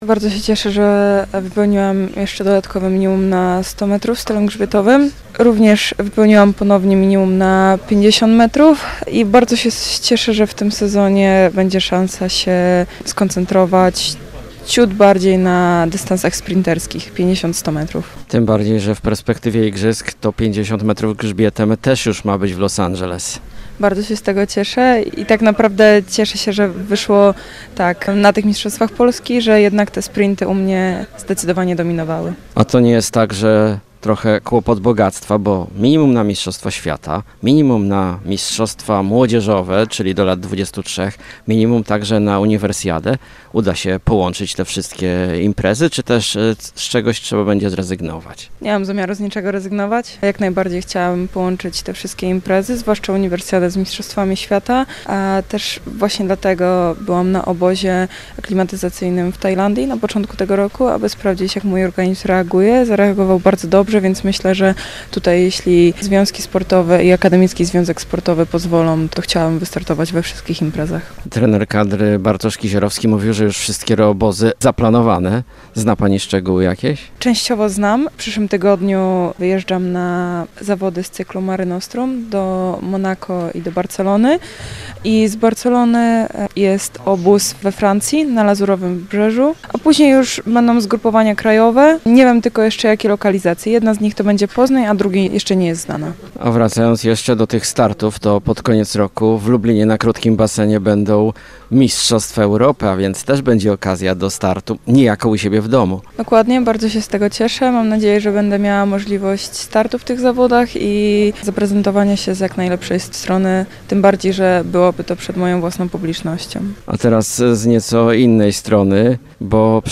Cała rozmowa w materiale dźwiękowym: